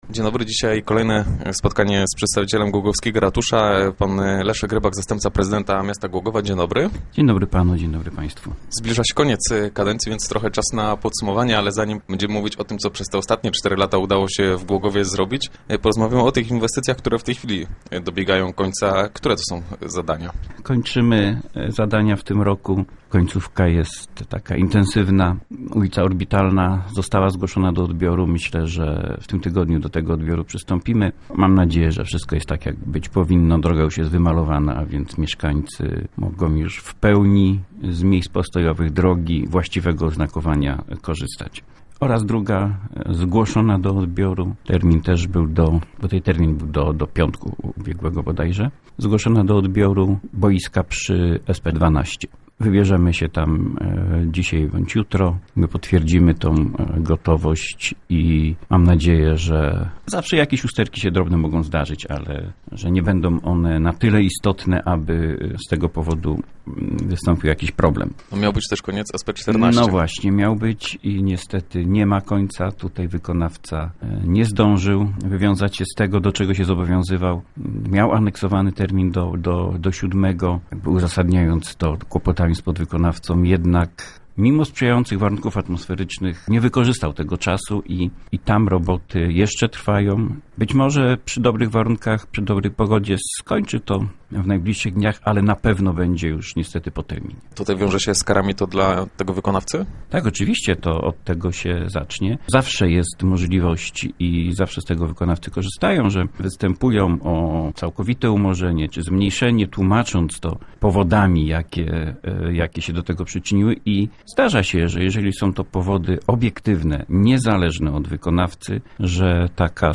O najważniejszych zadaniach i tych, których realizacja właśnie dobiega końca, mówił w naszym studio podczas cotygodniowej audy...